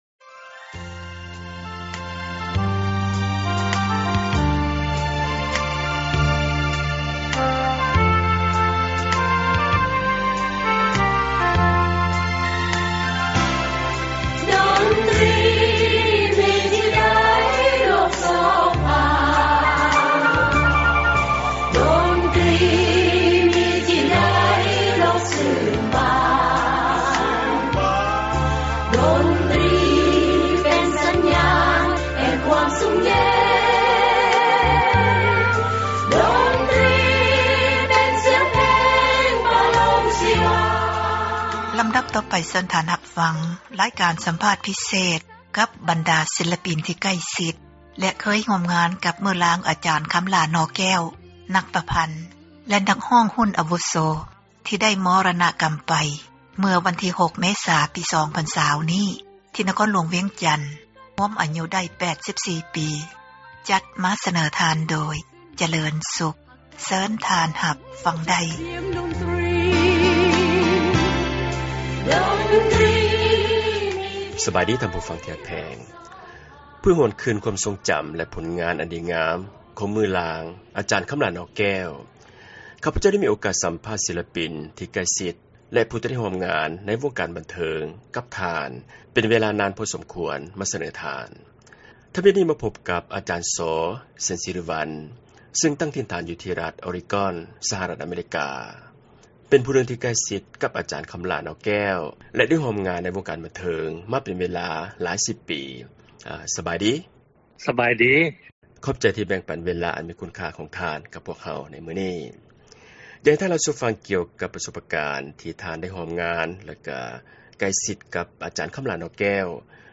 ການສັມພາດ ພິເສດ ກັບ ບັນດາ ນັກສິລປິນ ທີ່ໃກ້ຊິດ ແລະ ເຄີຍຮ່ວມງານ ກັບ ມື້ລາງ ອາຈານ ຄຳຫລ້າ ໜໍ່ແກ້ວ, ນັກປະພັນ, ນັກຮ້ອງ ອາວຸໂສ ທີ່ໄດ້ເຖິງແກ່ ມໍຣະນະກັມ ໄປ ເມື່ອວັນທີ 6 ເມສາ ປີ 2020 ນີ້ ທີ່ ນະຄອນຫຼວງວຽງຈັນ, ຮວມອາຍຸ ໄດ້ 84 ປີ.